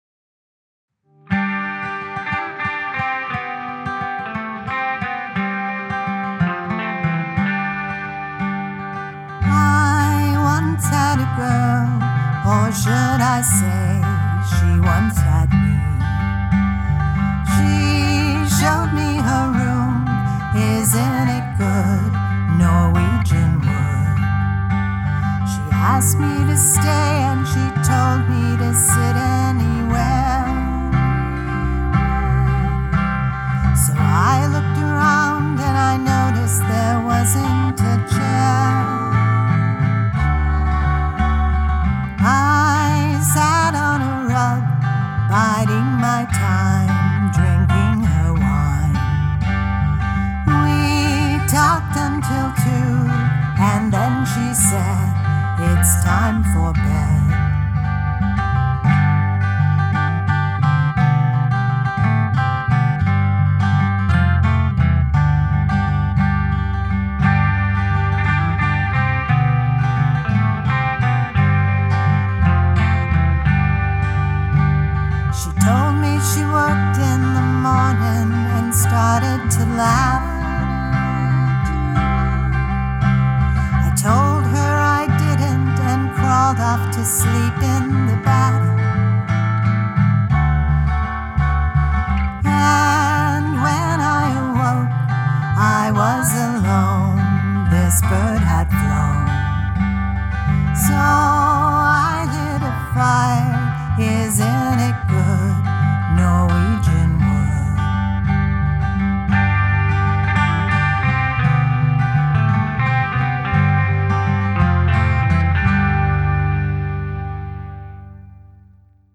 Cool vocals and harmonies and beautiful guitar sounds.